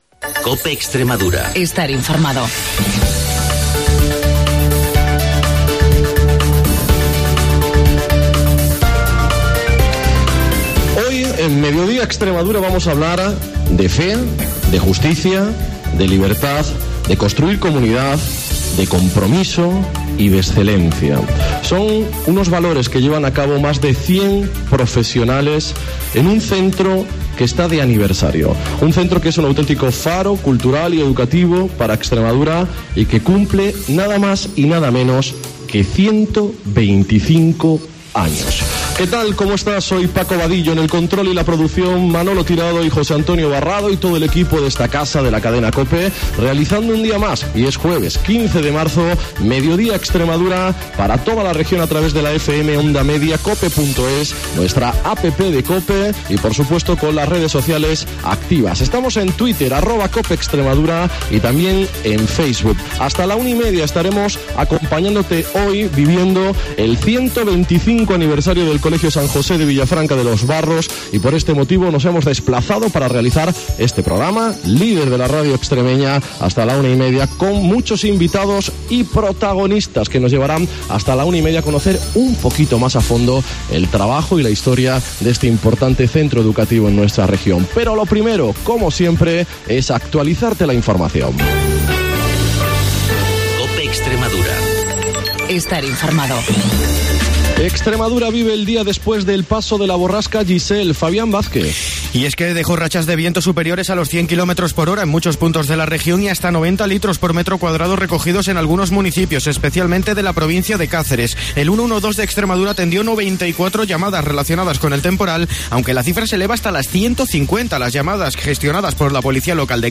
El programa líder de la radio extremeña
Hoy hemos realizado el programa en directo desde el colegio San José de Villafranca de los Barros para celebrar junto a ellos el 125 aniversario del centro.